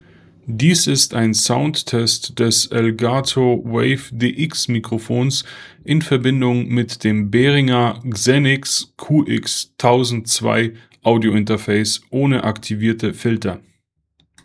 Soundtest
Softwareseitig ist die Lautstärke auf 60 % eingestellt und die Aufnahmedistanz beträgt etwa 20 Zentimeter.
Test 5: Behringer XENYX QX1002 ohne Filter